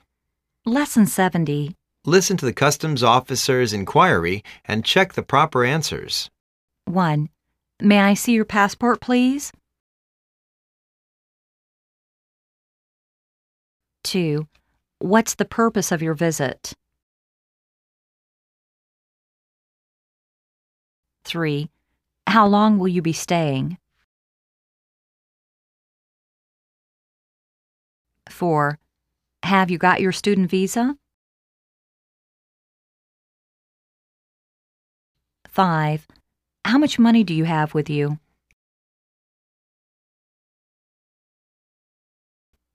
Listen to the customs officer's enquiry and check the proper answers.